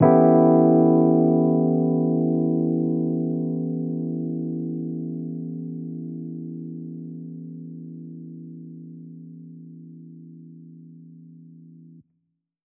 JK_ElPiano2_Chord-Em13.wav